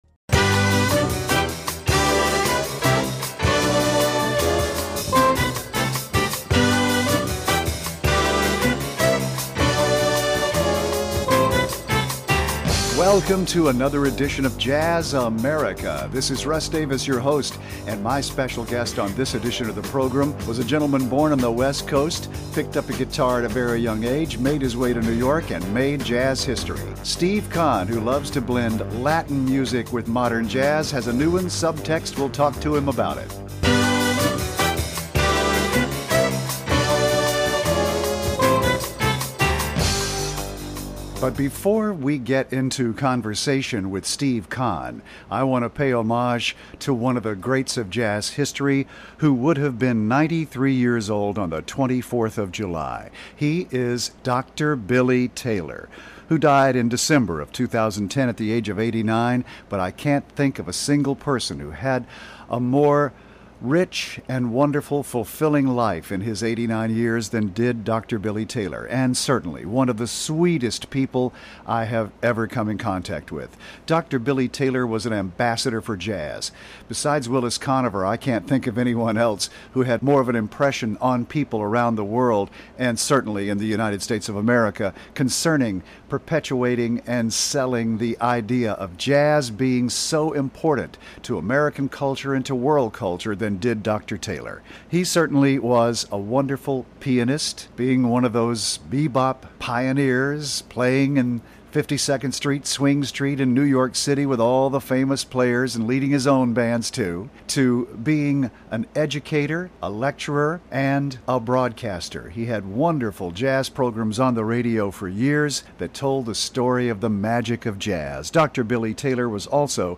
the best in jazz, present and past
interviews a musician and features music from their latest recordings